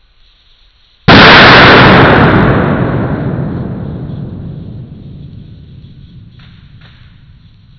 Close Close Volume warning: very loud!